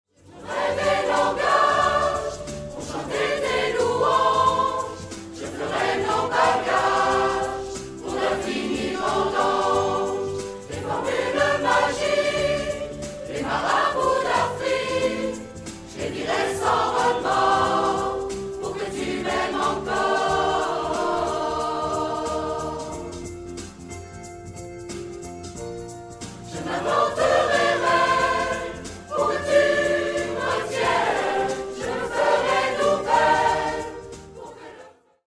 CAC Forbach
extraits audio du concert :